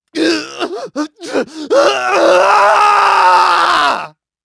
Clause-Vox-Story-Pain_2.wav